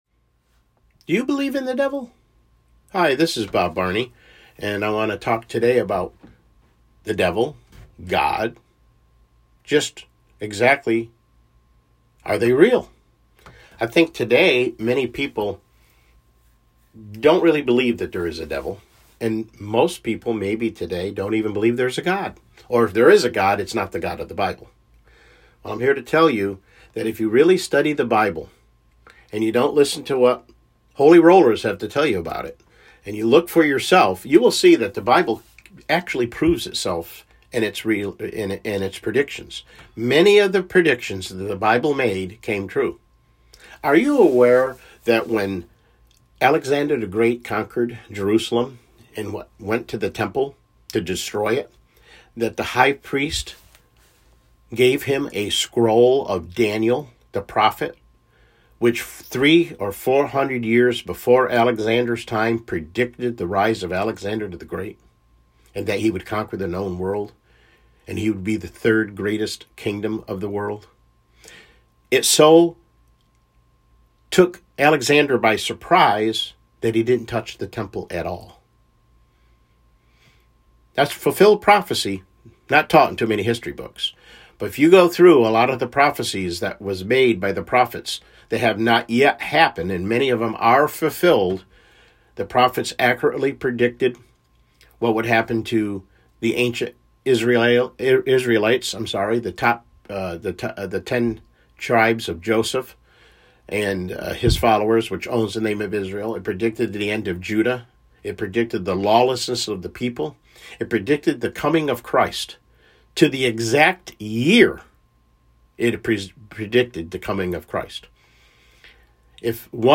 CLICK HERE TO LISTEN TO THE PLAIN TRUTH TODAY MIDDAY BROADCAST: Is the Devil Real?